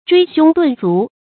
椎胸顿足 zhuī xiōng dùn zú
椎胸顿足发音